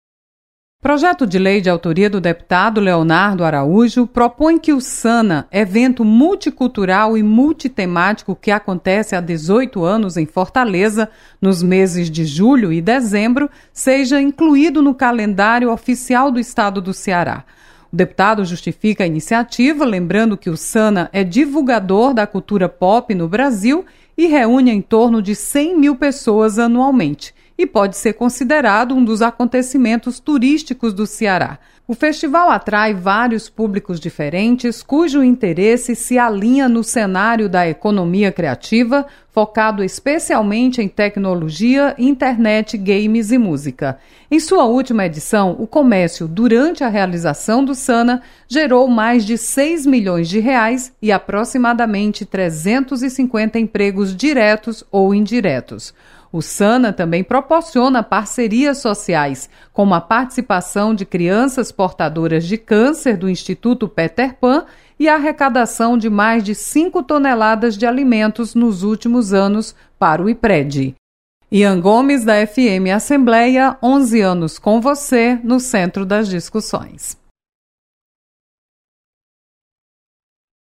Projeto reconhece importância econômica e cultural do Sana. Repórter